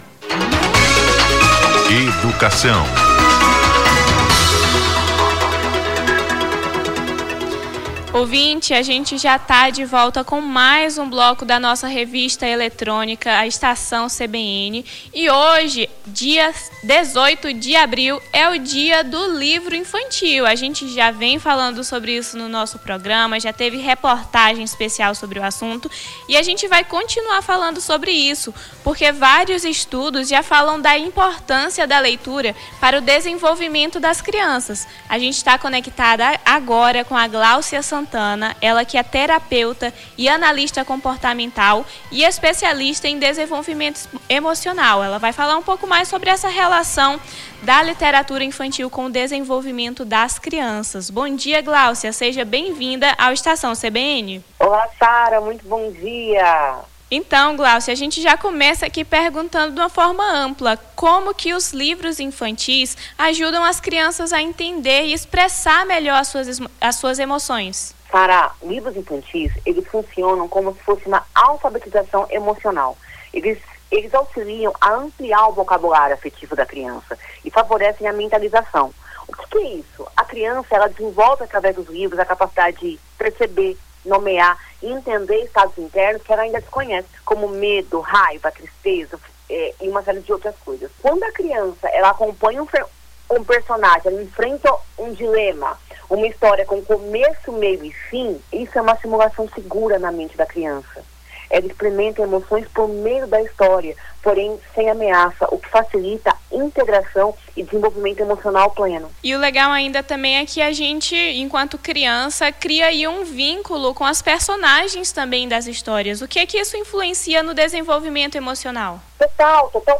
Na manhã deste sábado, 20, conversamos com a terapeuta, analista comportamental e especialista em desenvolvimento emocional